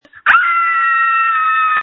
This is just a sample of the many screams recorded on January 20, 2021.
• When you call, we record you making sounds. Hopefully screaming.